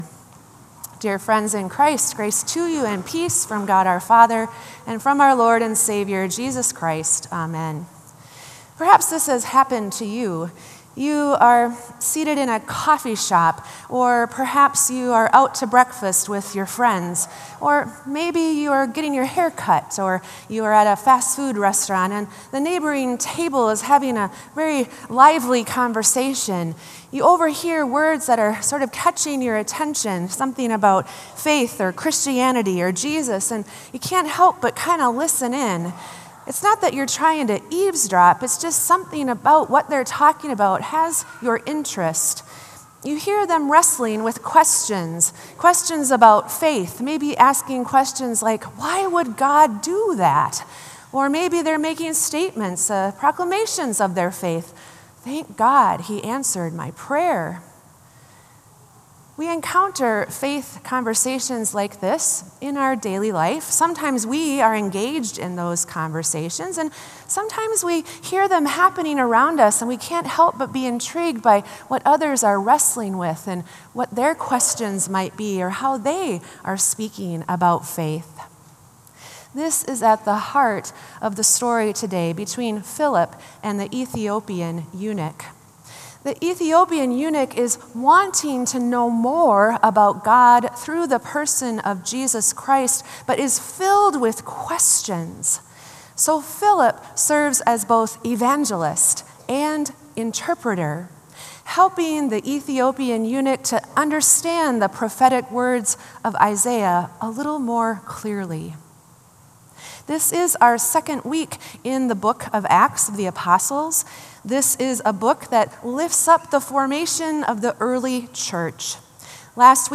Sermon “Meeting on the Road”